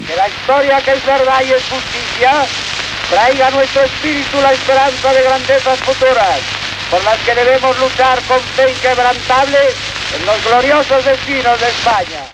Paraules del rei Alfonso XIII el dia de la inauguració de l'Exposició Internacional de Barcelona.